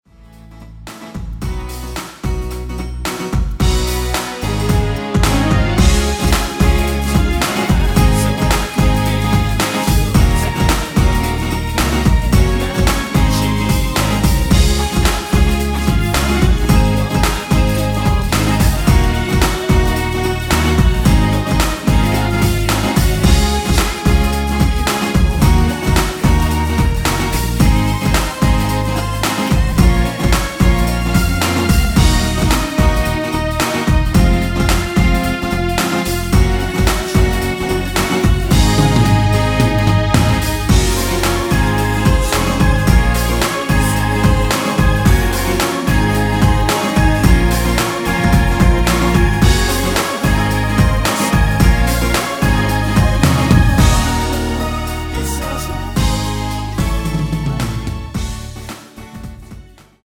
원키 코러스 포함된 MR입니다.
앞부분30초, 뒷부분30초씩 편집해서 올려 드리고 있습니다.